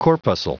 Prononciation du mot corpuscle en anglais (fichier audio)